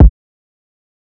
Kick (Gorgeous).wav